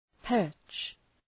Προφορά
{pɜ:rtʃ}